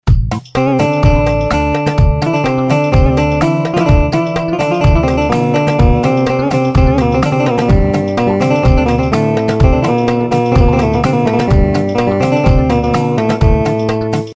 Asagida Dinlediginiz Sample Sesleri direk Orgla Calinip MP3 Olarak Kayit edilmistir
Elektro Baglama 1